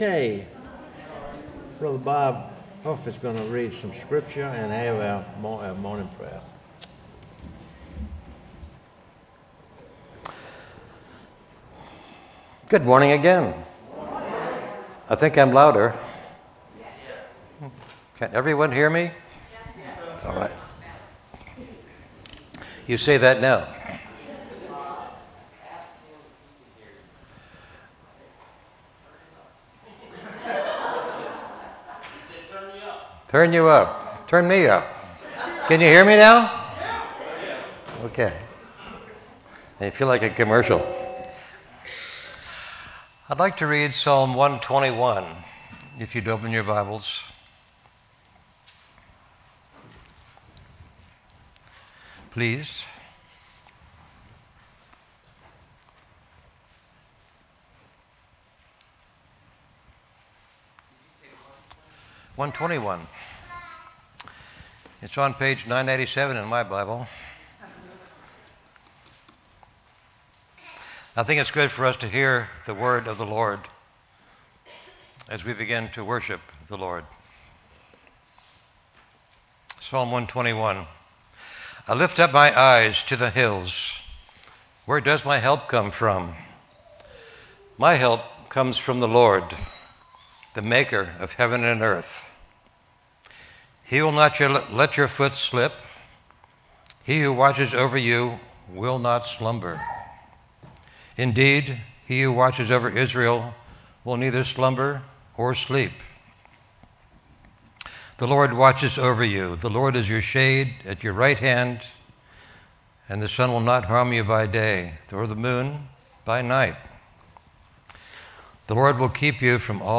sermonSept1-1-CD.mp3